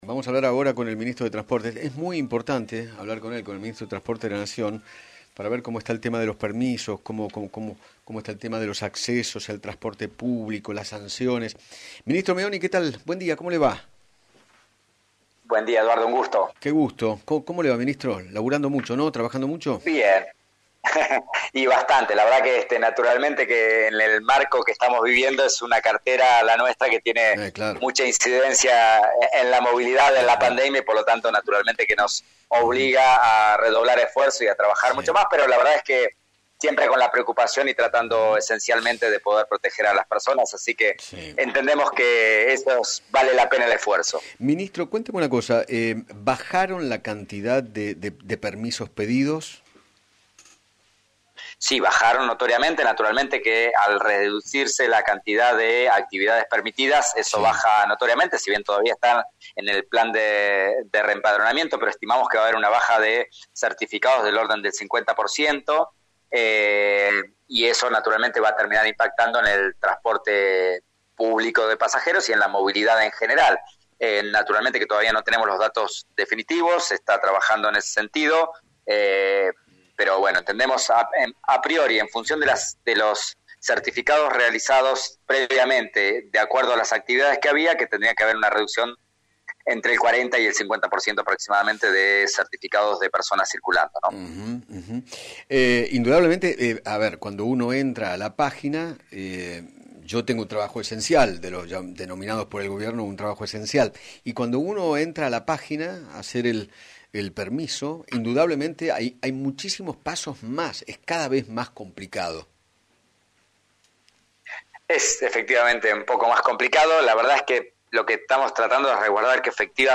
Mario Meoni, ministro de Transporte de la Nación, dialogó con Eduardo Feinmann sobre los nuevos permisos para circular y se refirió a las posibles sanciones que tendrán aquellos que no cumplan con la documentación. Además, explicó cómo será el sistema de la SUBE.